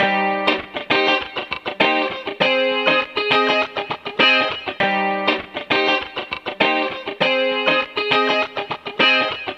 Sons et loops gratuits de guitares rythmiques 100bpm
Guitare rythmique 43